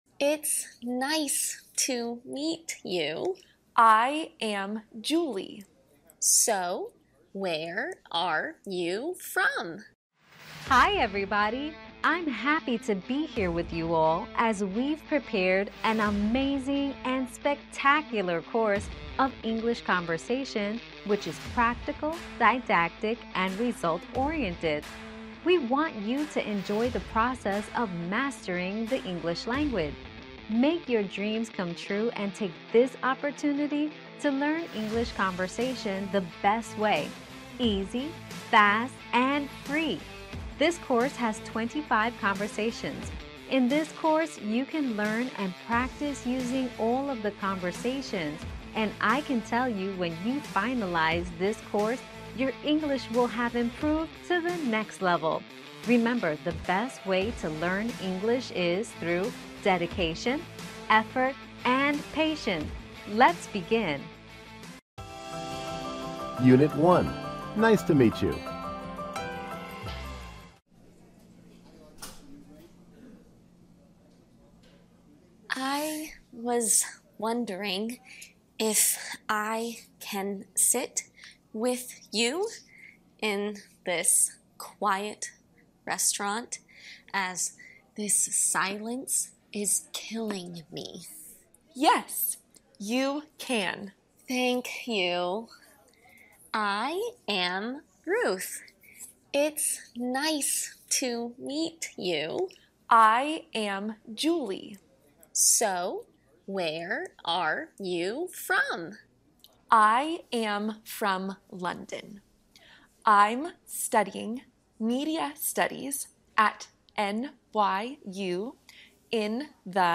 Conversación en inglés para principiantes: curso básico lento y fácil (¡imposible no entender!)